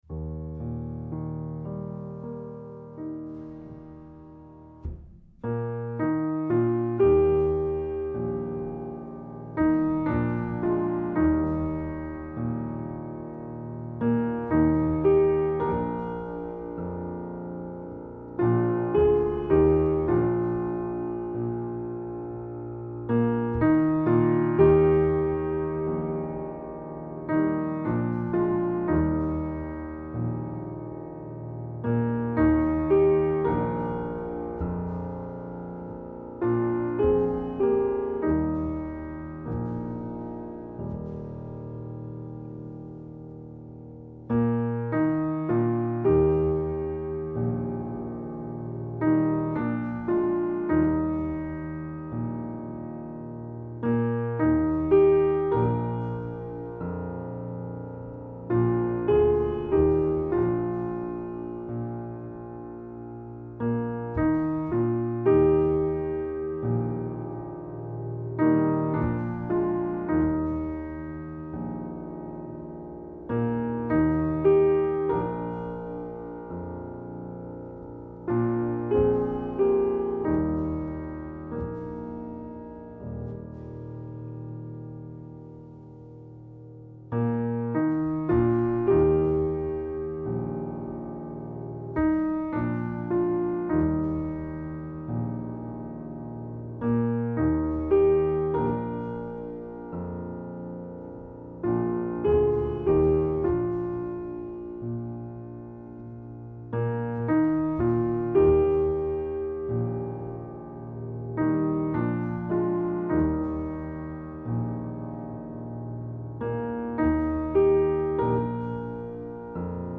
Atem-Ton-Bewegung